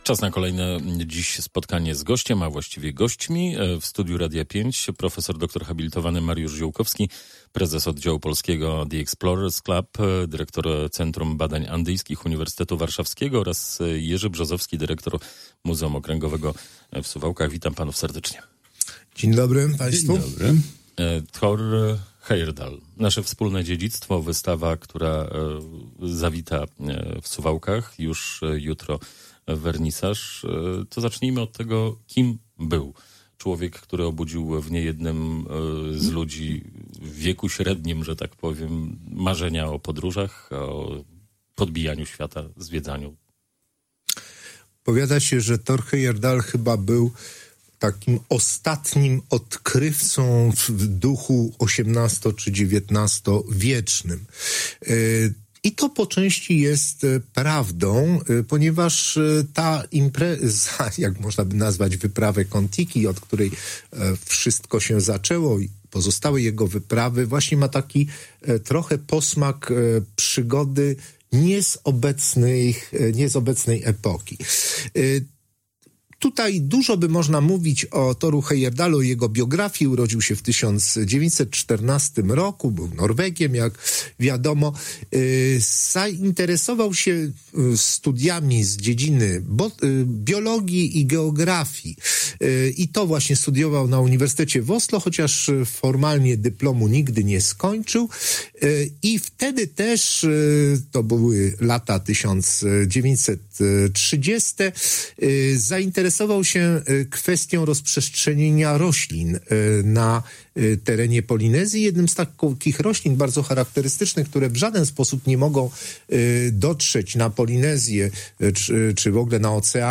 Na wystawę poświęconą wyprawom Thora Heyerdahla zapraszali w Radiu 5